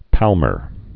(pălmər, päl-, pämər)